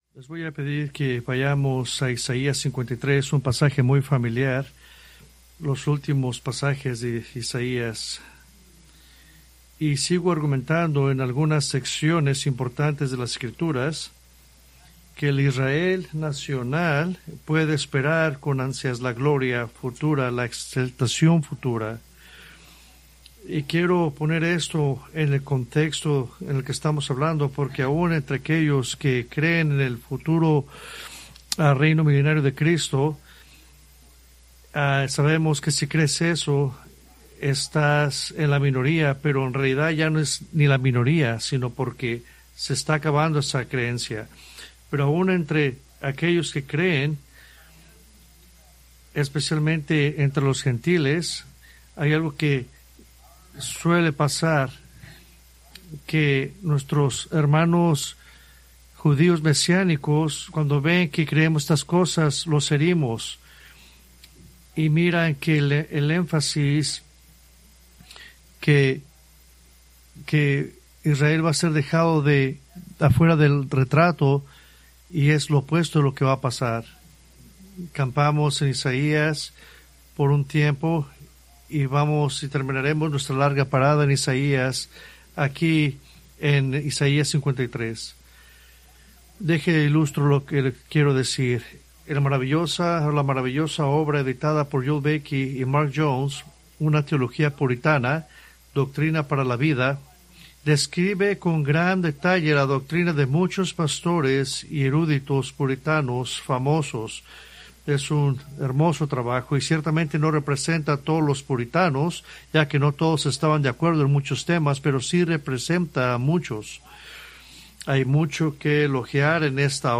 Preached December 7, 2025 from Escrituras seleccionadas